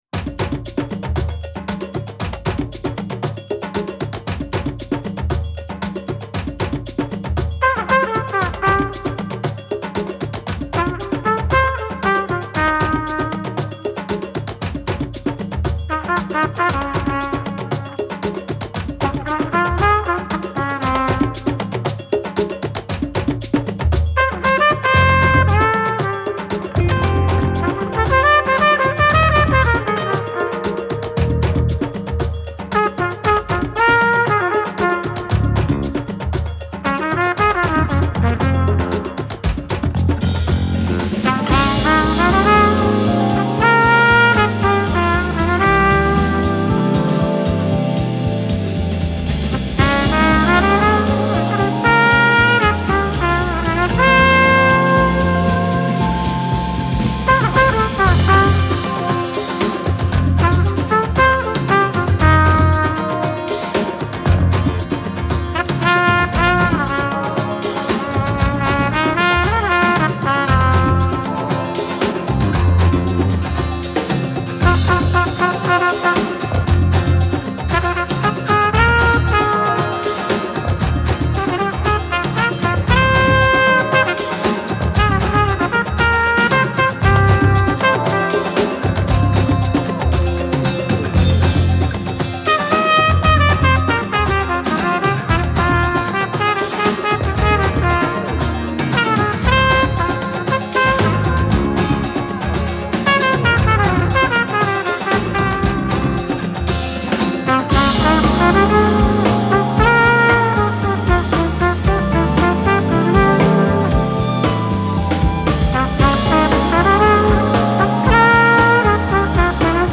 Genre : Contemporary Jazz